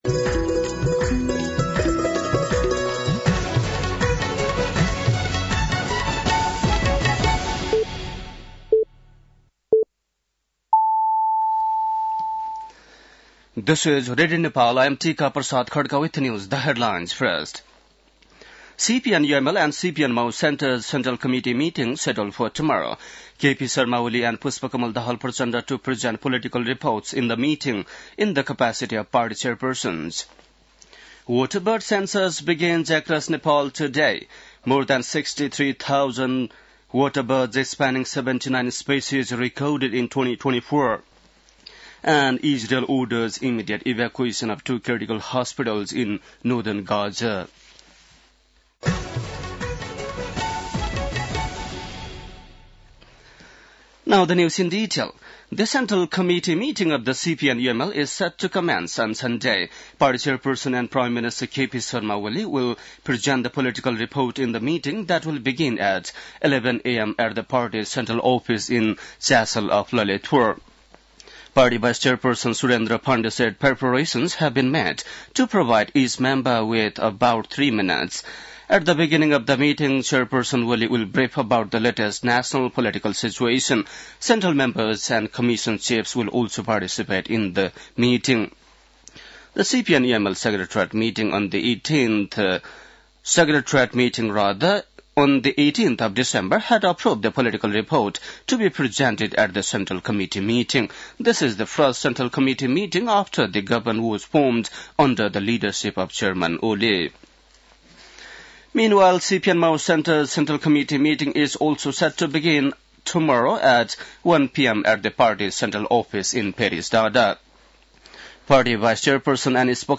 बेलुकी ८ बजेको अङ्ग्रेजी समाचार : २१ पुष , २०८१